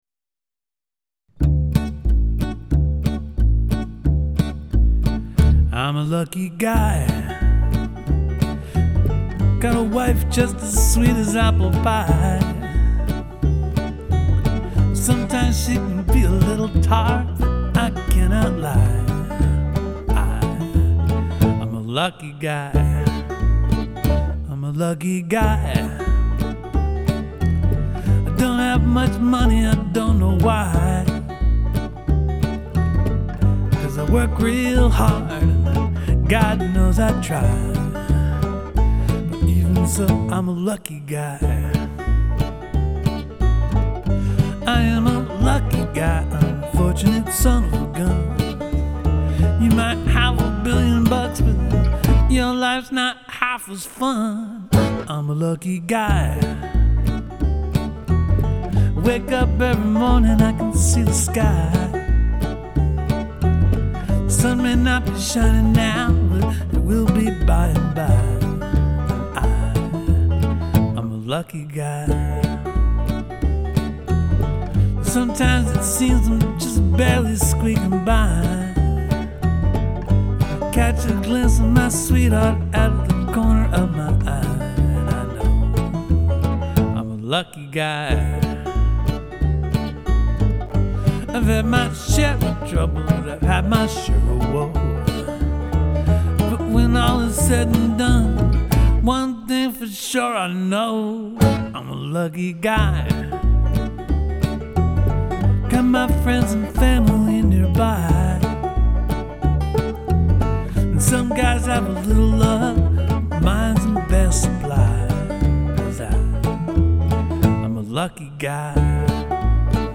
[ ruff basics - not for sale or distribution ]